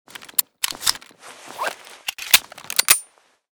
cz52_reload_empty.ogg.bak